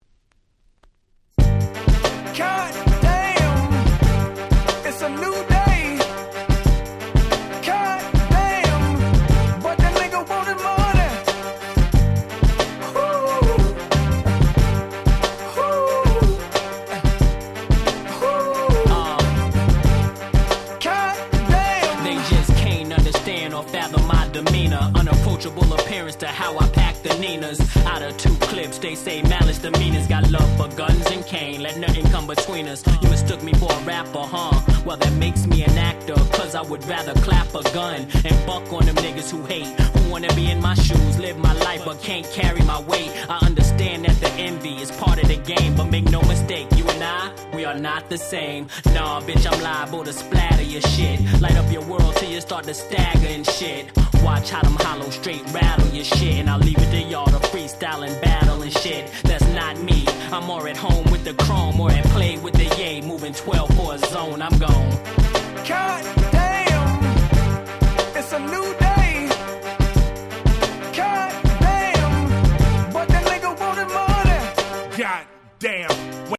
02' Smash Hit Hip Hop !!